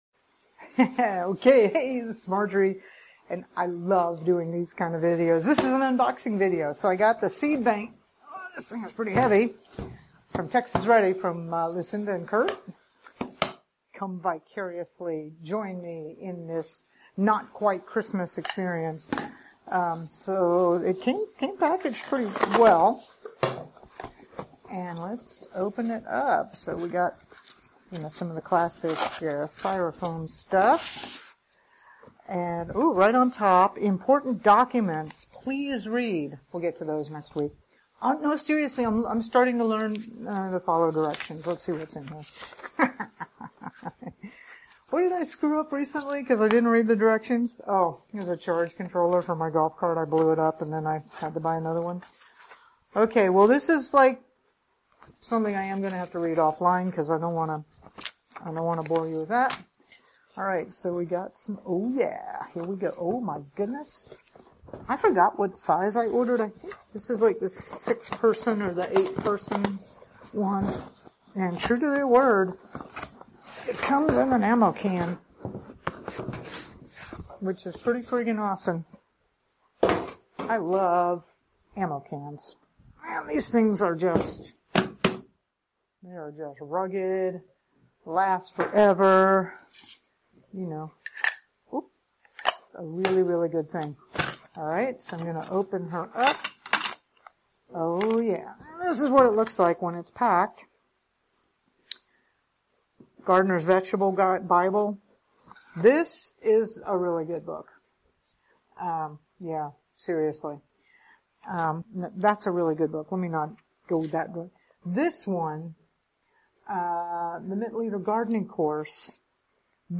unboxing-a-texas-ready-seed-vault.mp3